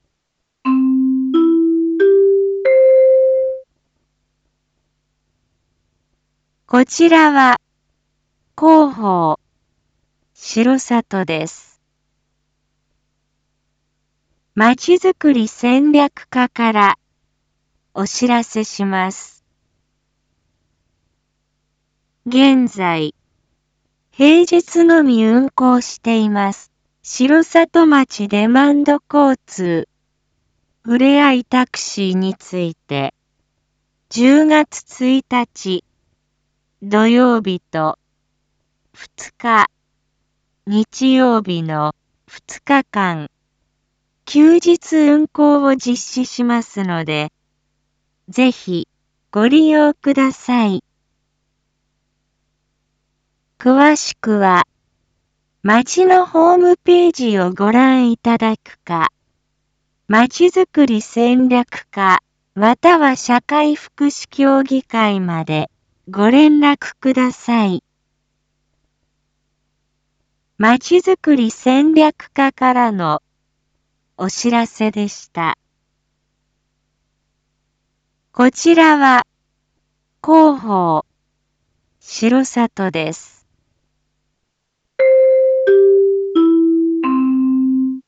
一般放送情報
Back Home 一般放送情報 音声放送 再生 一般放送情報 登録日時：2022-09-28 19:01:29 タイトル：R4.9.28 19時放送分 インフォメーション：こちらは広報しろさとです。